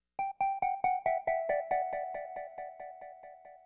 Counter Melody.wav